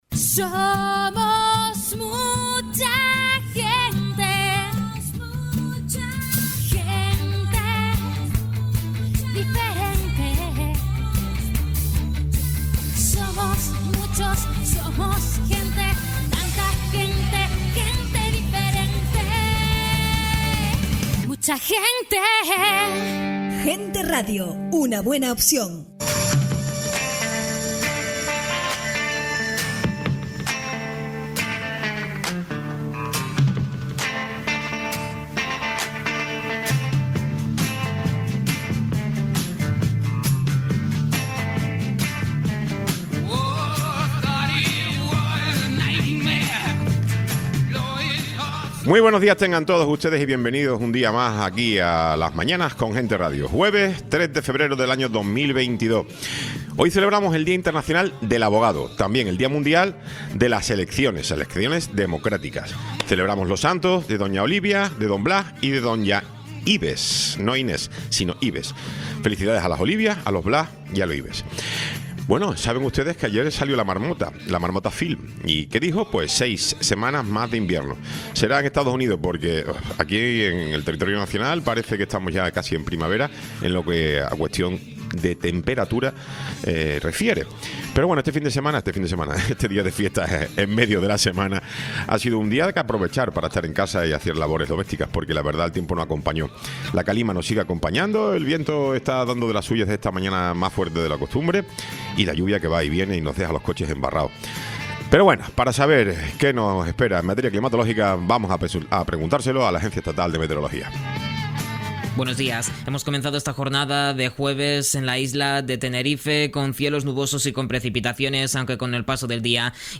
Tertulia
Tiempo de entrevista